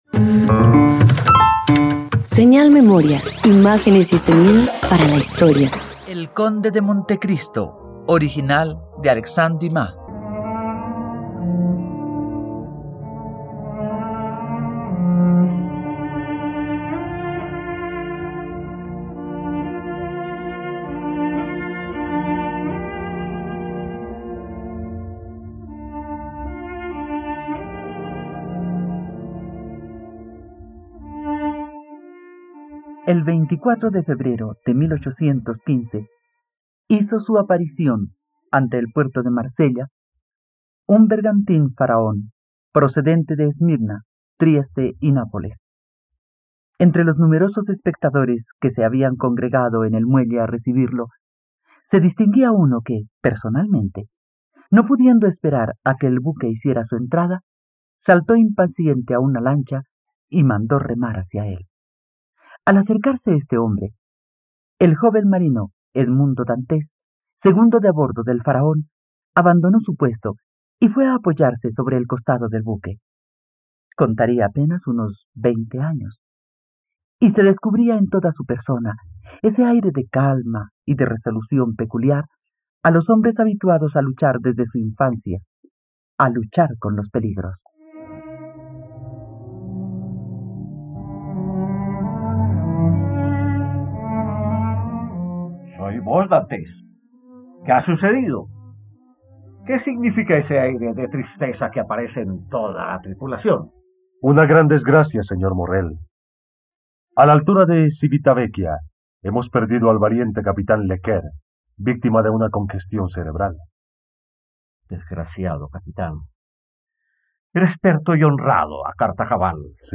El conde de monte Cristo - Radioteatro dominical | RTVCPlay